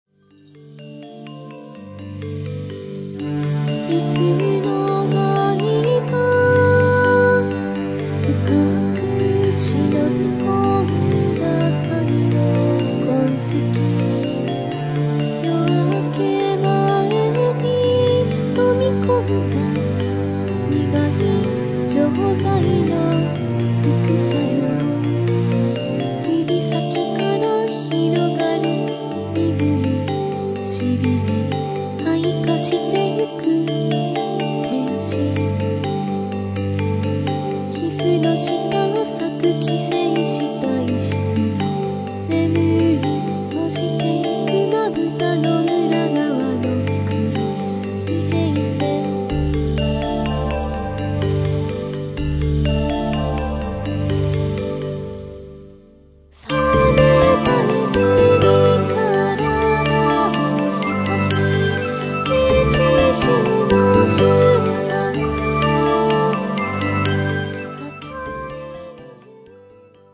電脳空間を浮遊する女声Voが物語る博物誌